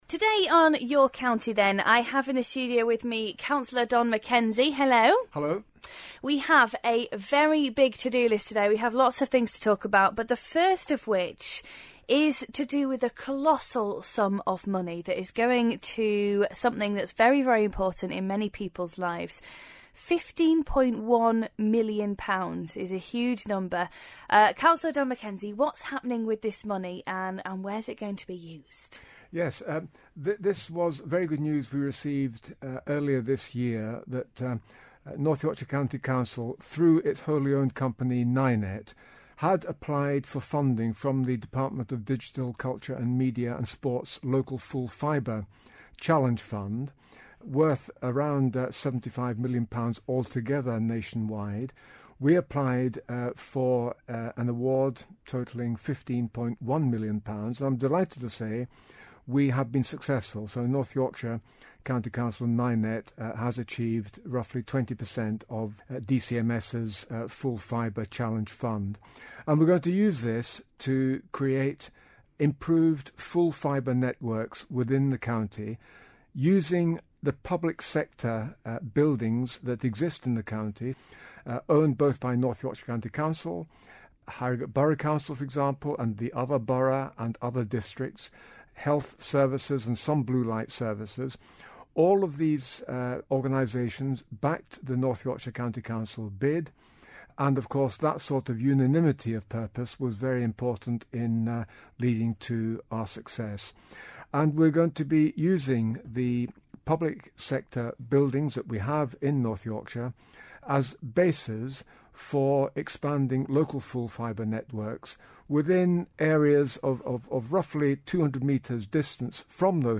Councillor Don Macenzie explains the improvements